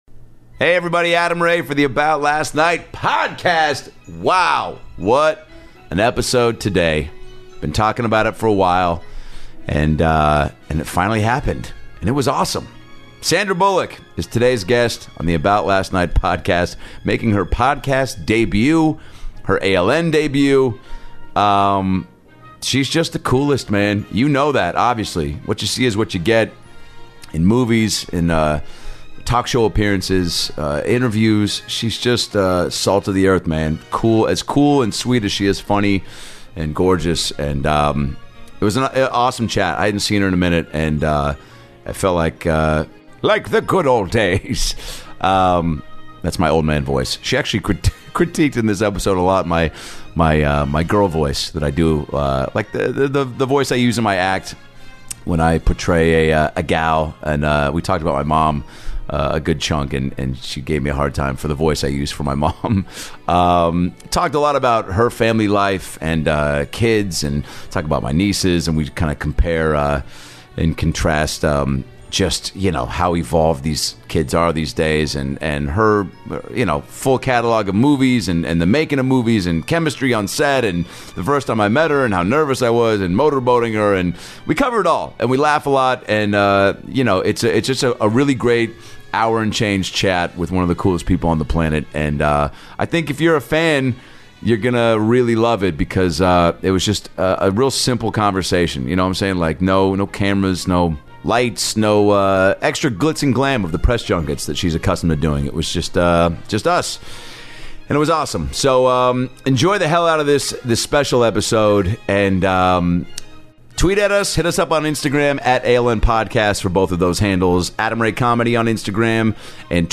Sandra Bullock makes her podcast debut for an amazing one on one chat with Adam Ray! From prepping for her role in The Blind Side, to the worlds of motherhood, to why she won’t sing karaoke, to her naked scene with Ryan Reynolds, getting motor boated by Adam in The Heat, and takes some fan questions! It’s an incredibly fun & candid interview with one of the sweetest and most talented people on the planet!